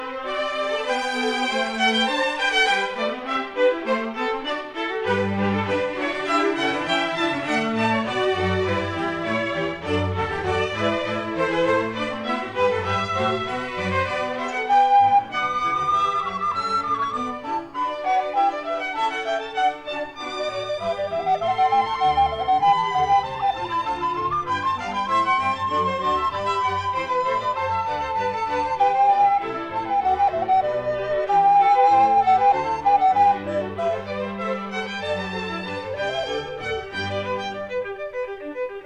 in G major
(recorders)
(harpsichord)
1960 stereo recording made by